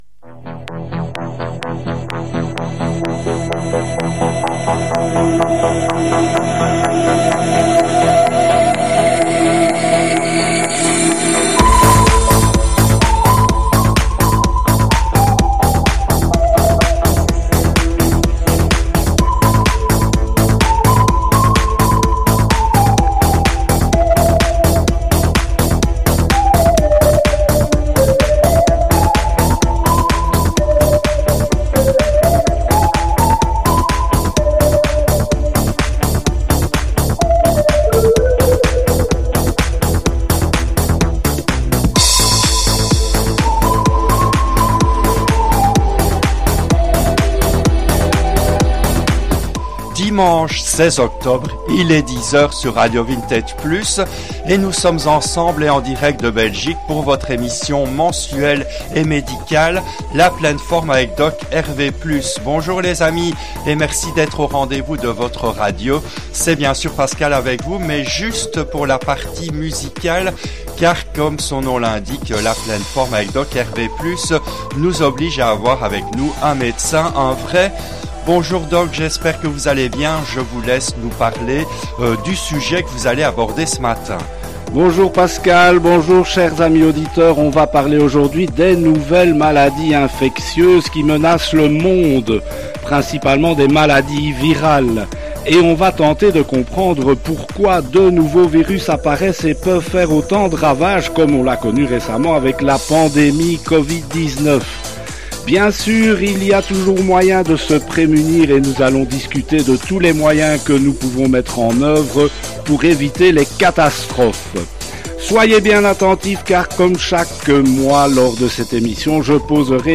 RADIO VINTAGE PLUS a donc voulu faire le point à ce sujet pour tous les auditeurs, dans une émission qui a été diffusée en direct le dimanche 16 octobre 2022 à 10 heures depuis les studios belges de RV+.